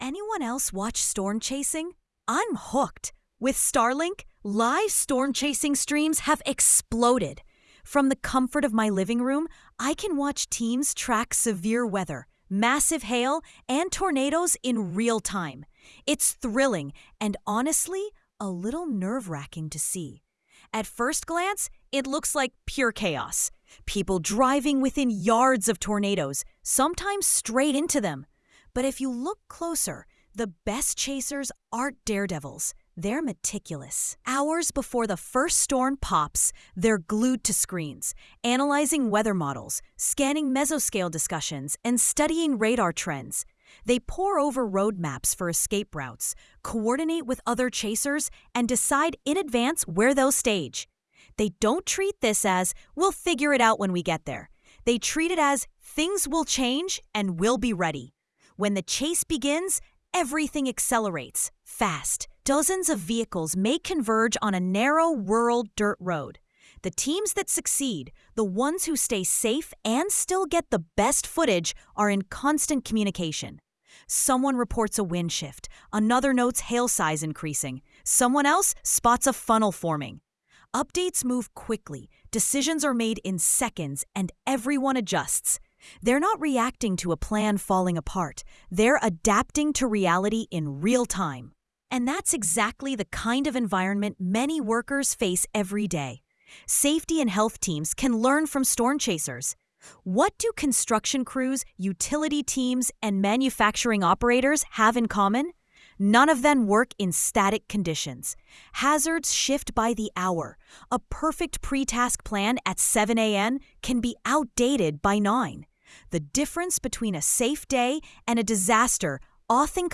sage_gpt-4o-mini-tts_1x_2025-08-13T07_11_04-519Z.wav